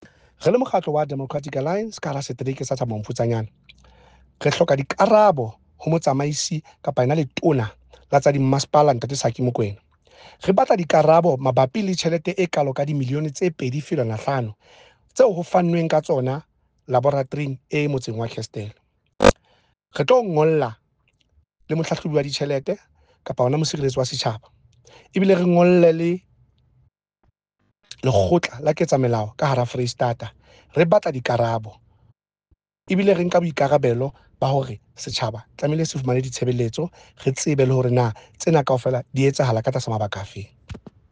Sesotho soundbite by Cllr Eric Motloung.